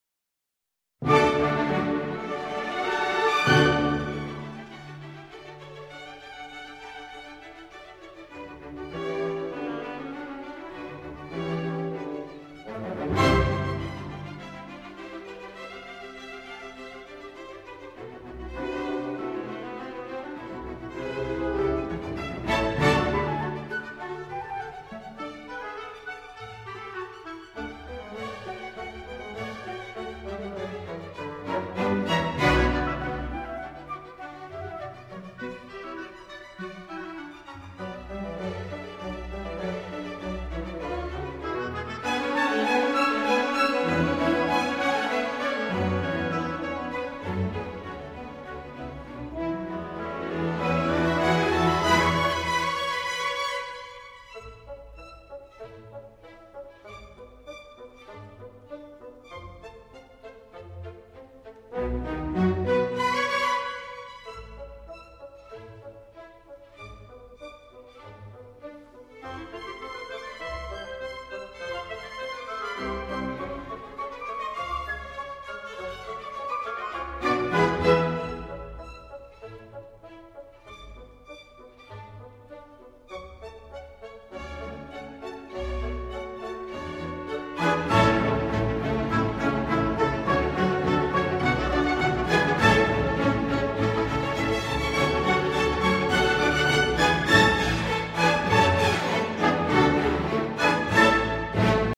1986 Half note = 100